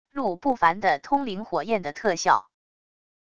陆不凡的通灵火焰的特效wav音频